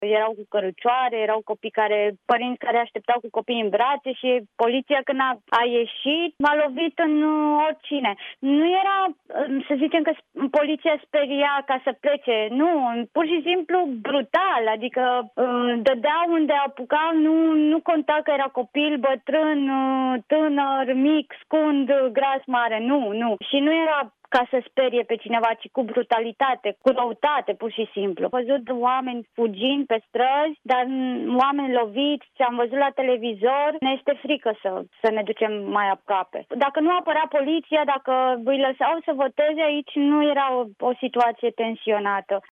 1-oct-20-romanca-despre-violentele-din-catalonia.mp3